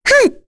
Rephy-Vox_Jump2.wav